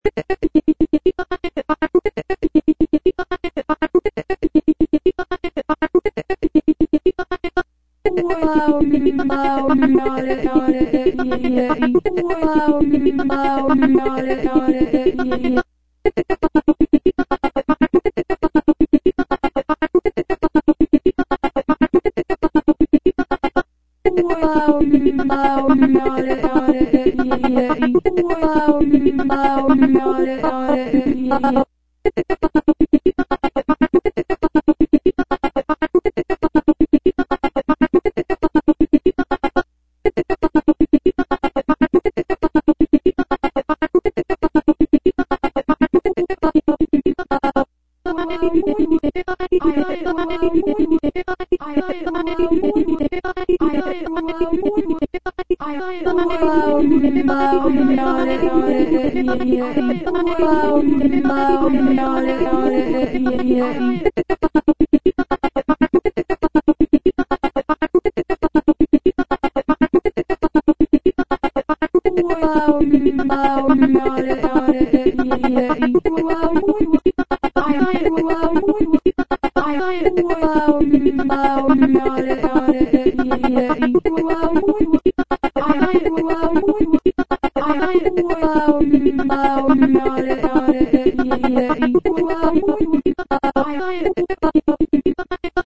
vocal generated compositions.
I typed hundreds of vowel combinations to try and extract simple
Later I went back and put many of them together to compose my melodies.